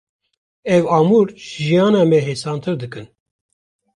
/ɑːˈmuːɾ/